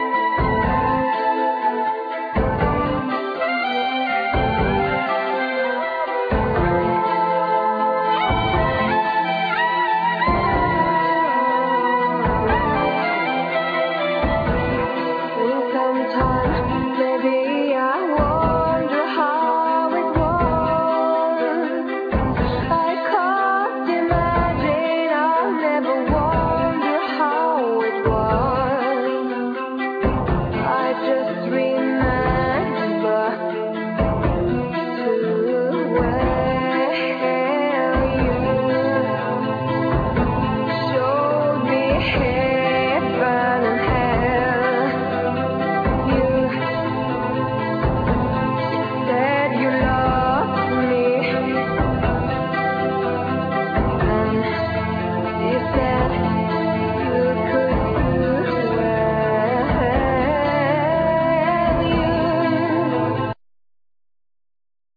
Vocal, Synthsizer
Synthsizer, Computer programming
Mandlin, Bass, Flute, Synthsizer, Programming